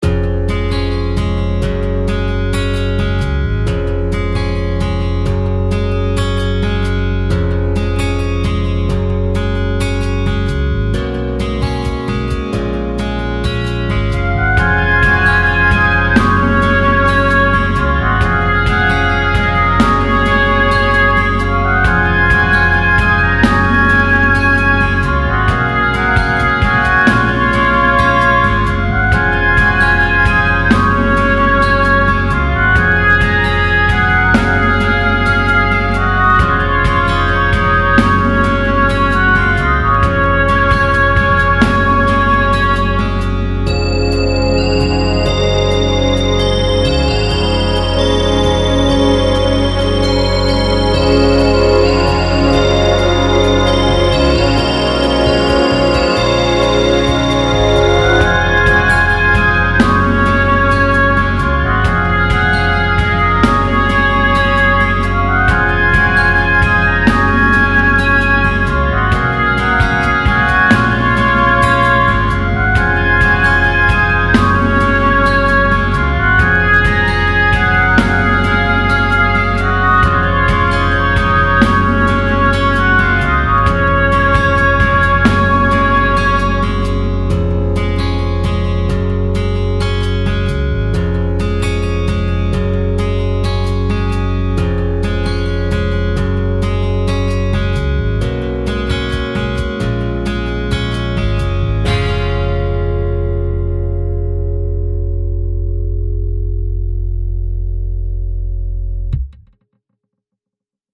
昨日のは、聴きなおしていくうちにメロディの音割れが気になったので手を加えることにした。
ついでに各楽器の音量バランスやＥＱを多少弄った。間奏部分でのギタートラックを消した。